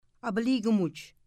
Mi’kmaw Pronunciations for Teaching About the Mi’kmaq